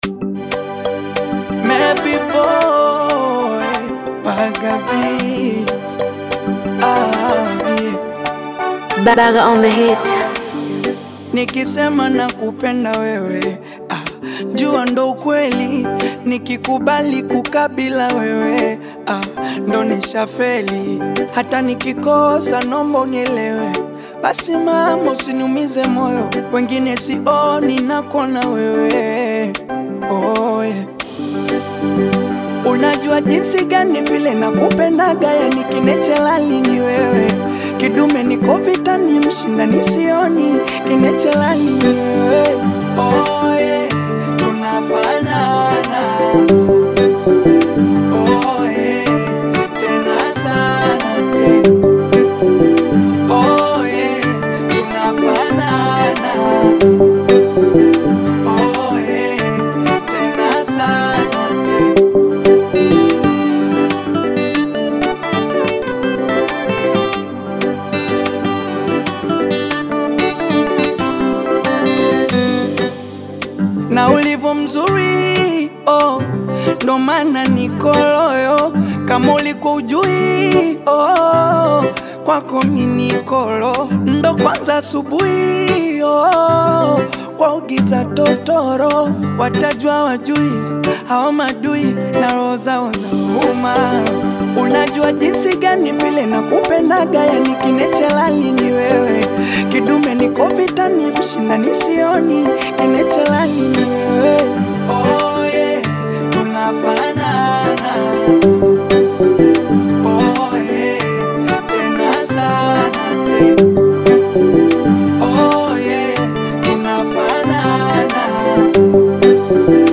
Bongo Flava music track
acoustic version
love song
This catchy new song